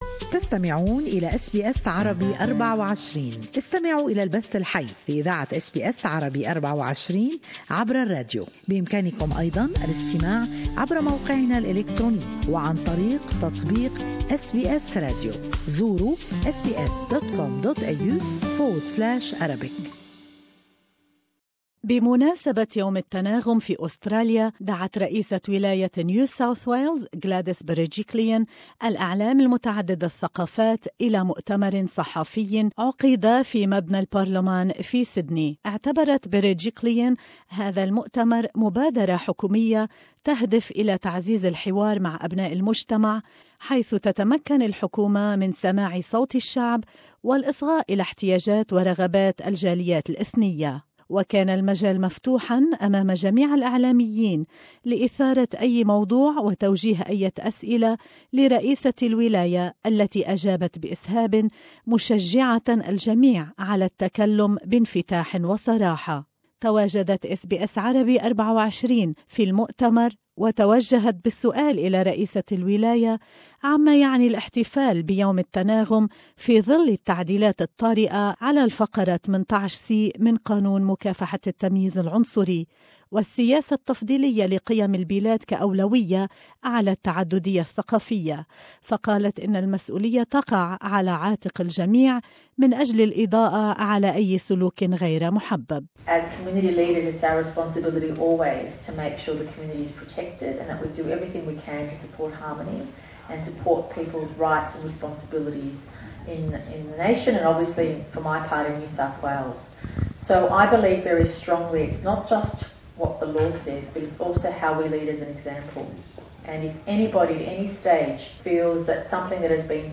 Harmony day press conference web